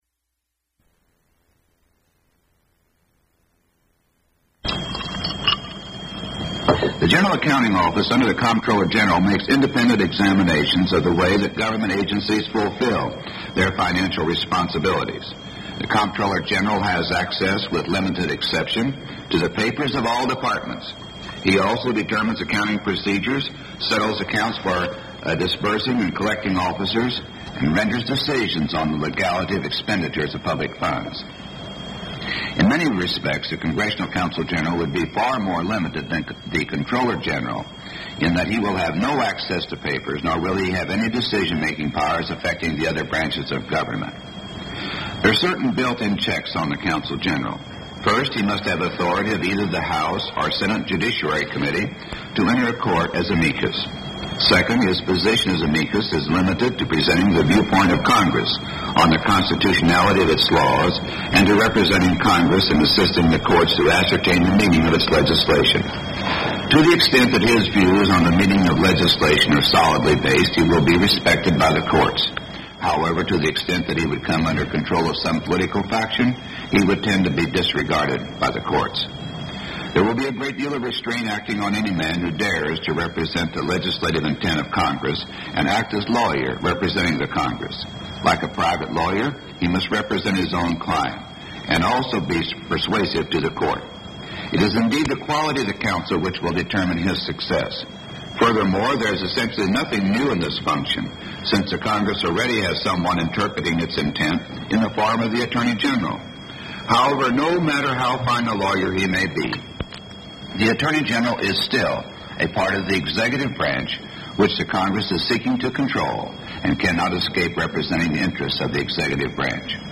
Subjects Watergate Affair (1972-1974) Politics and government United States Material Type Sound recordings Language English Extent 00:30:29 Venue Note Broadcast 1974 March 26.